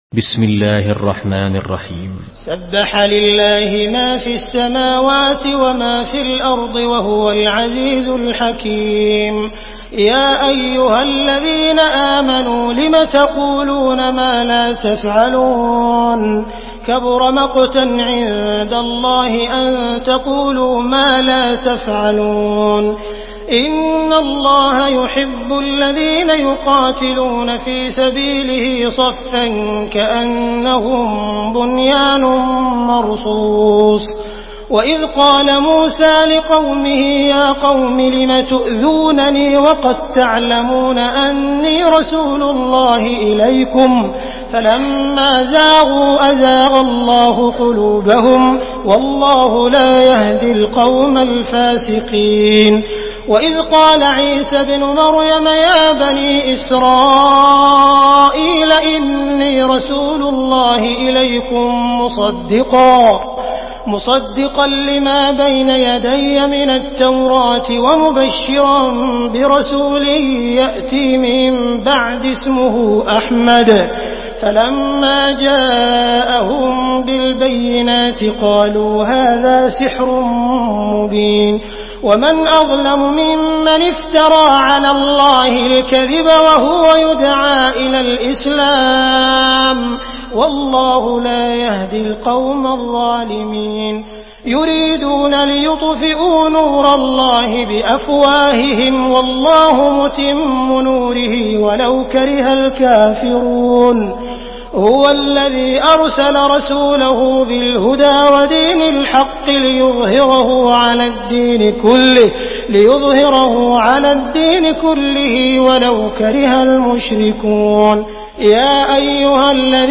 Surah Saf MP3 by Abdul Rahman Al Sudais in Hafs An Asim narration.
Murattal Hafs An Asim